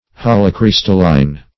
Search Result for " holocrystalline" : The Collaborative International Dictionary of English v.0.48: Holocrystalline \Hol`o*crys"tal*line\, a. [Holo + crystalline.]